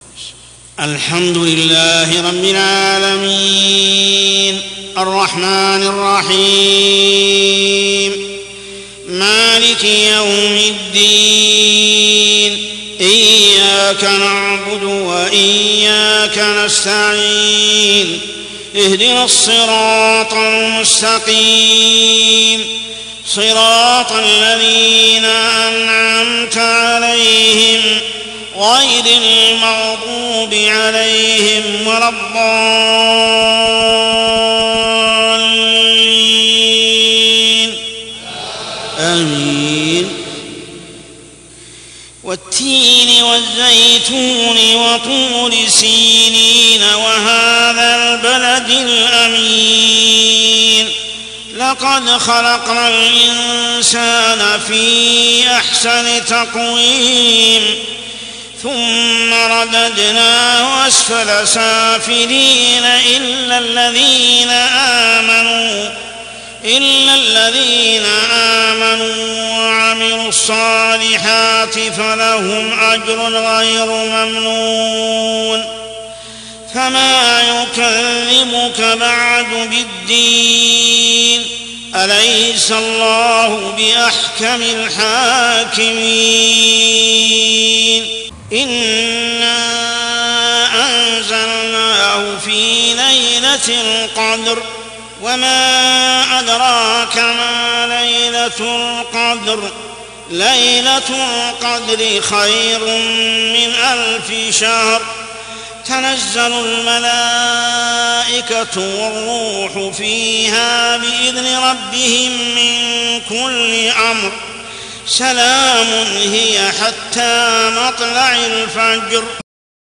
عشائيات شهر رمضان 1424هـ سورتي التين و القدر كاملة | Isha prayer surah at-Tin and al-qadr > 1424 🕋 > الفروض - تلاوات الحرمين